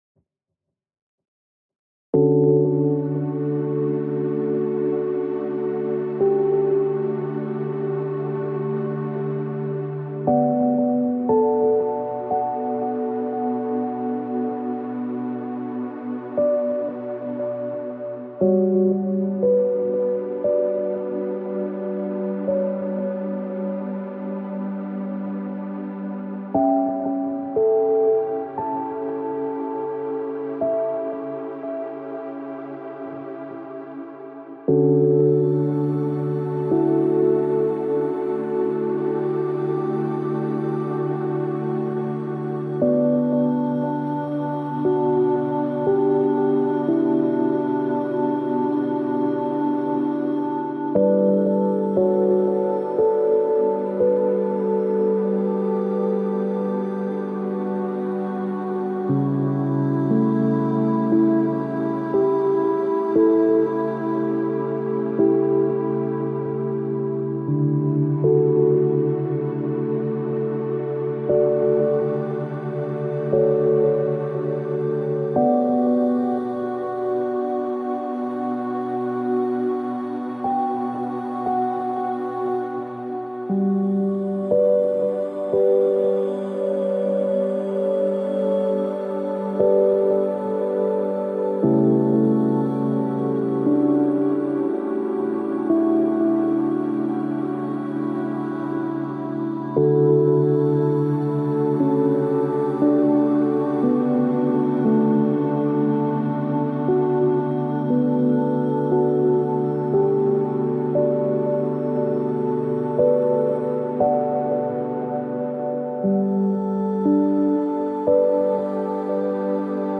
7hz - Theta Binaural Beats for Positive Energy ~ Binaural Beats Meditation for Sleep Podcast